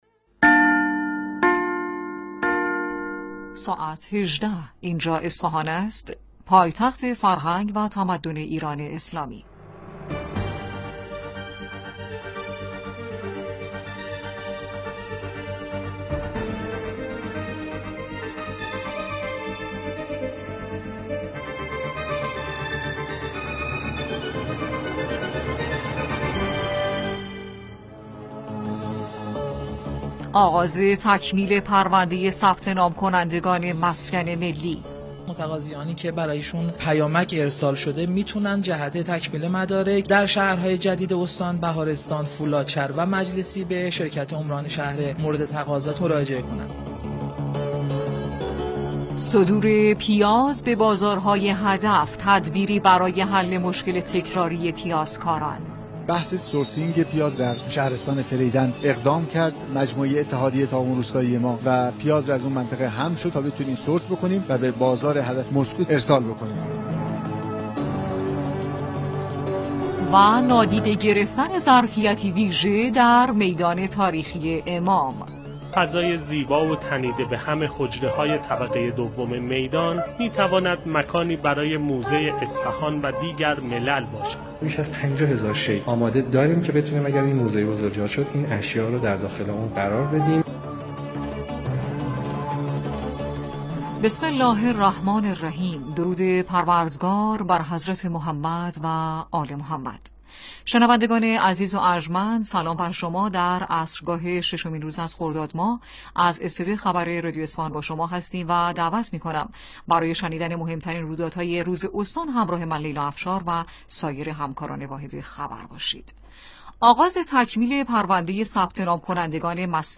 بخش خبری ۱۸ رادیو مرکز اصفهان در ششم خرداد